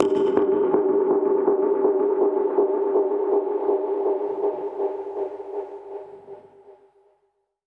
Index of /musicradar/dub-percussion-samples/125bpm
DPFX_PercHit_A_125-02.wav